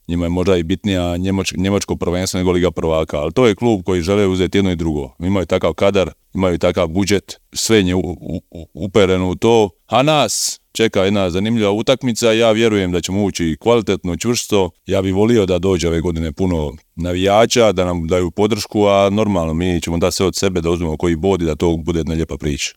Intervju Media servisa započeli smo s kapetanom Zagreba Jakovom Gojunom koji jedva čeka start sezone.